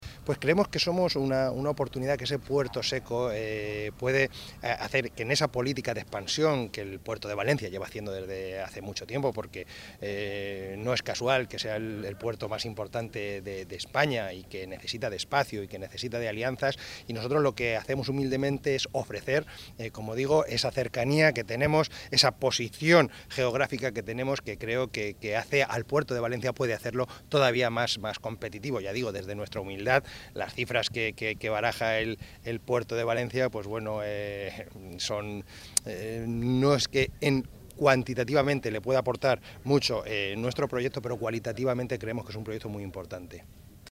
Declaraciones tras la visita
Presidente-Diputacion-Albacete-Santiago-Cabanero.mp3